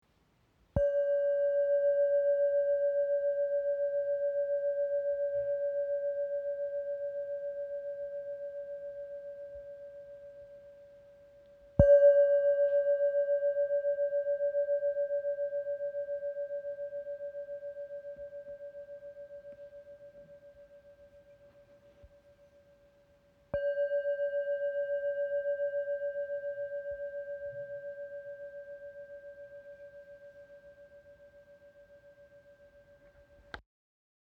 Bol chantant 7 métaux • Ré 575 Hz
Composé de sept métaux martelés à la main par des artisans expérimentés au Népal.
Note : Ré 575 Hz
Diamètre : 10,7 cm